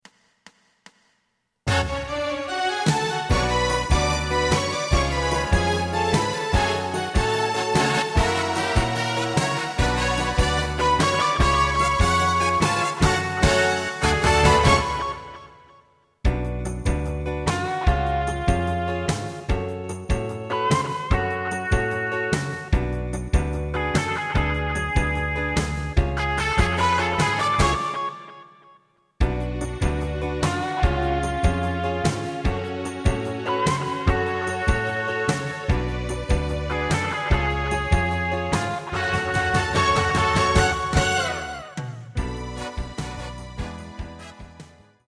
フルコーラス(カラオケ)